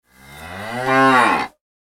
دانلود صدای گاو برای اس ام اس از ساعد نیوز با لینک مستقیم و کیفیت بالا
جلوه های صوتی